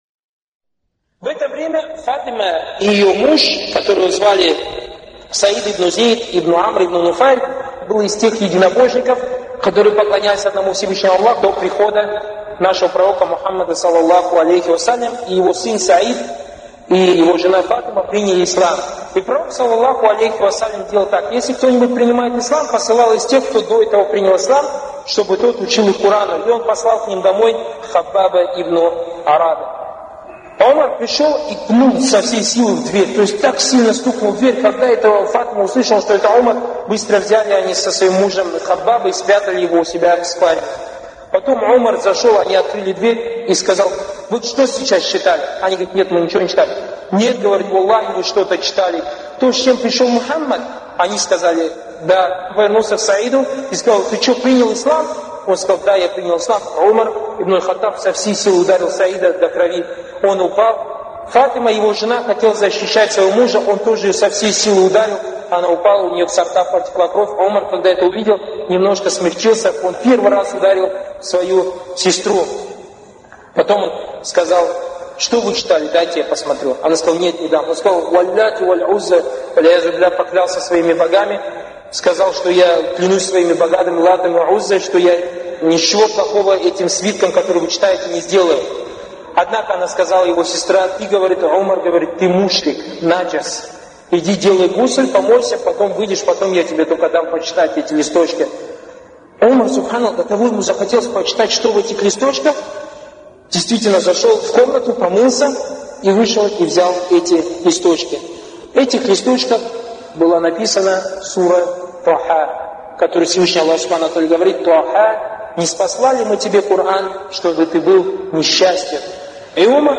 лекции Торик Суейдана (были приняты во внимание его ошибки, на которые указали учёные).